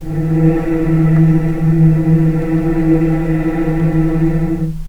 vc-E3-pp.AIF